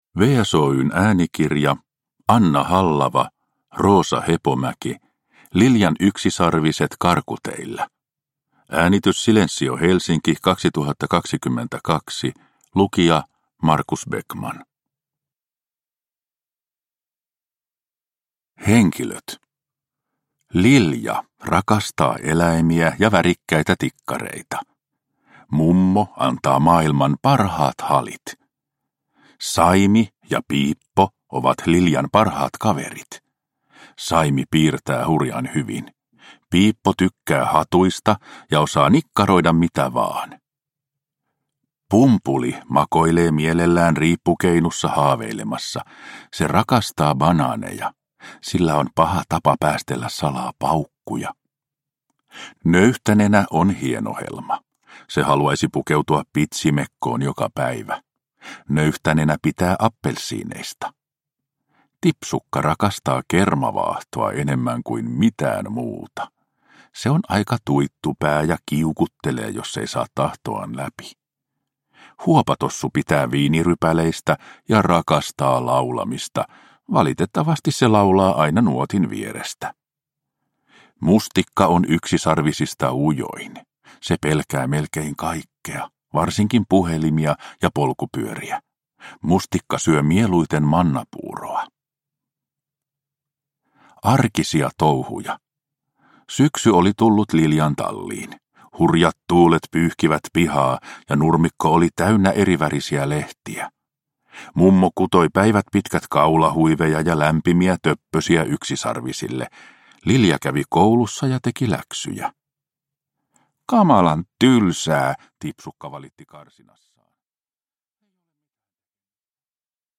Liljan yksisarviset karkuteillä – Ljudbok – Laddas ner